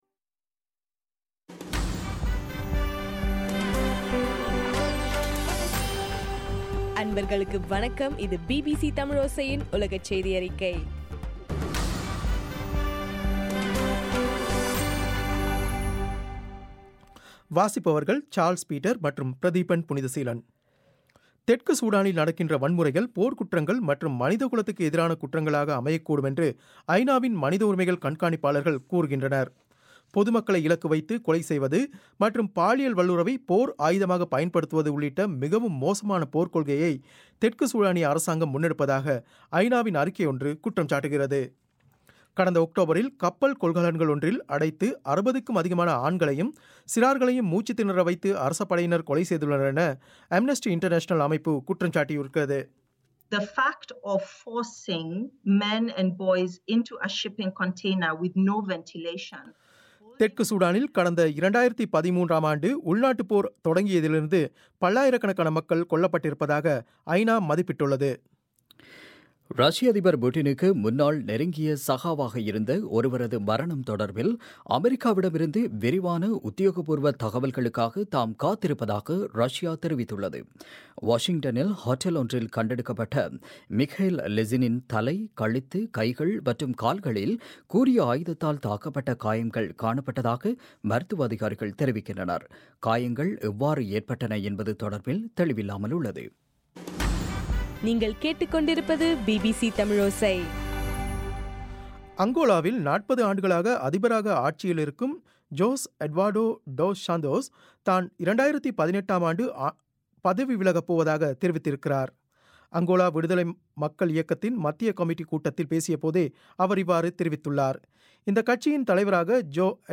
மார்ச் 11, 2016 பிபிசி தமிழோசையின் உலகச் செய்திகள்